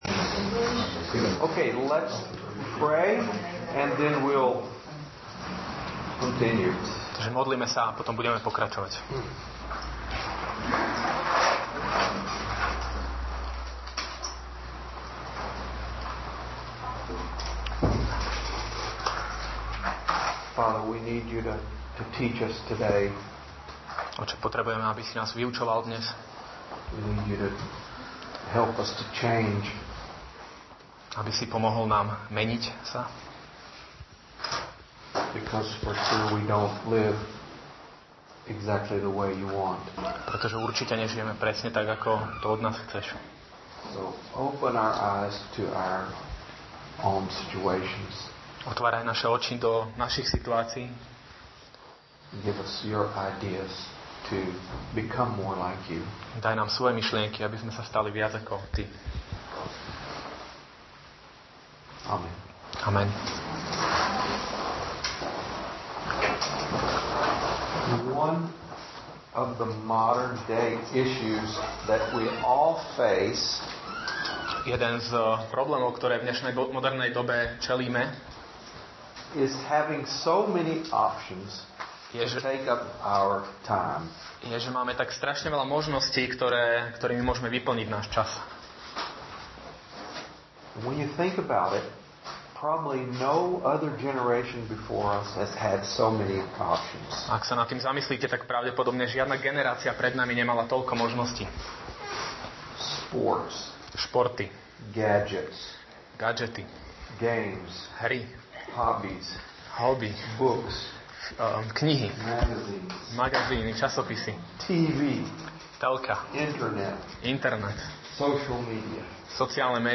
Nahrávka kázne Kresťanského centra Nový začiatok z 26. novembra 2017